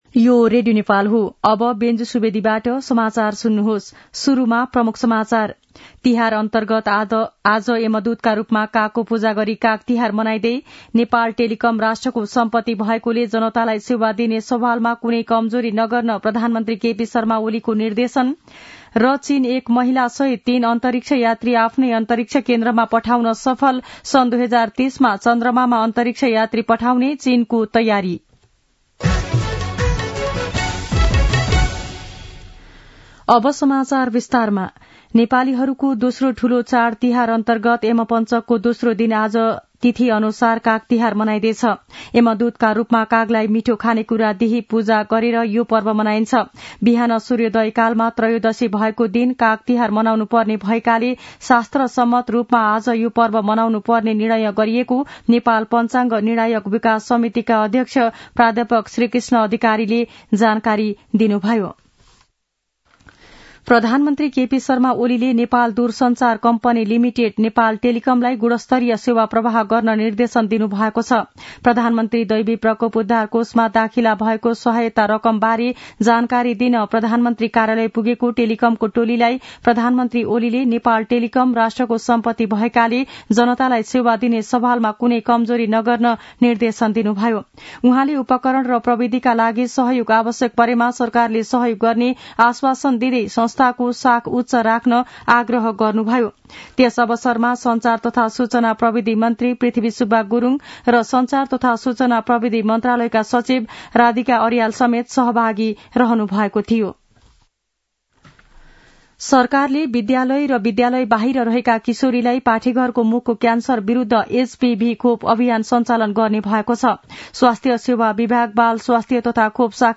दिउँसो ३ बजेको नेपाली समाचार : १५ कार्तिक , २०८१
3-pm-news-1-2.mp3